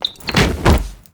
anonDumbassFall.ogg